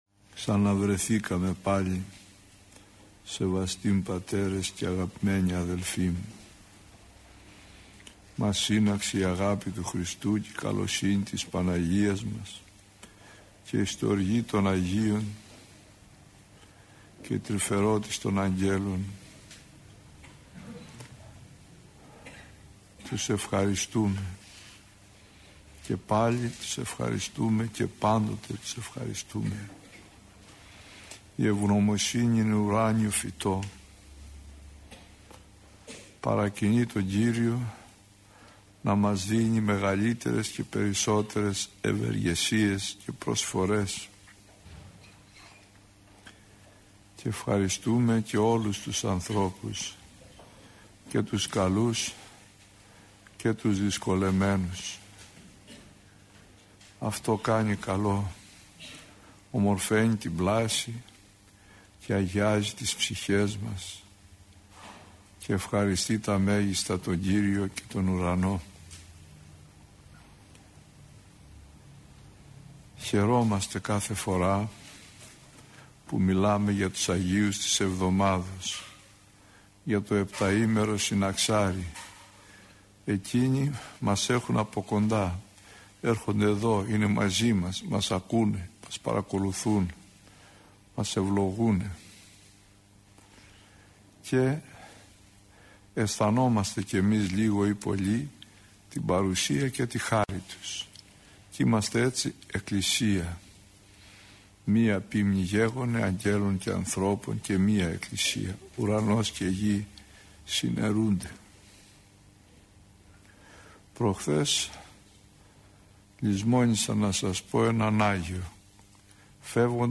ηχογραφημένη ομιλία
Η εν λόγω ομιλία αναμεταδόθηκε από τον ραδιοσταθμό της Πειραϊκής Εκκλησίας.